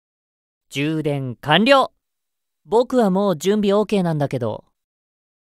Asmodeus_AP_Notification_Voice.ogg.mp3